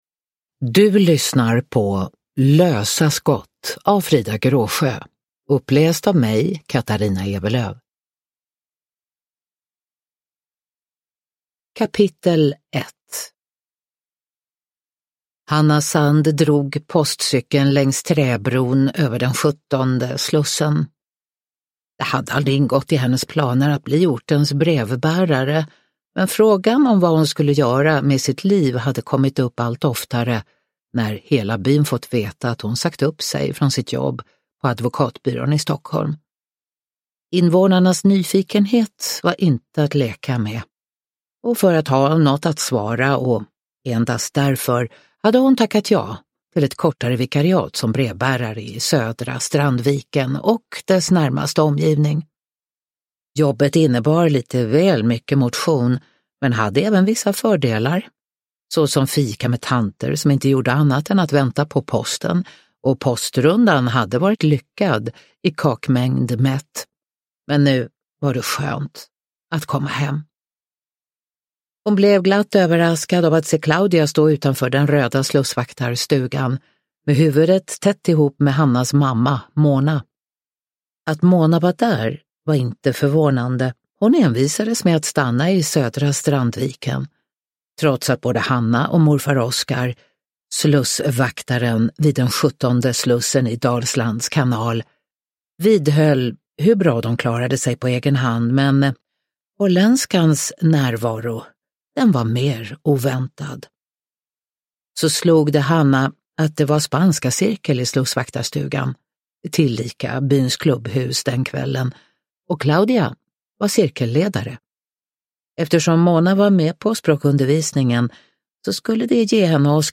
Uppläsare: Katarina Ewerlöf
Ljudbok